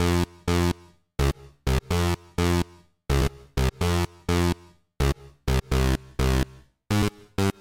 plucked terror loop
描述：A jolly gamingcartoon loop. Abuse of D chord.
声道立体声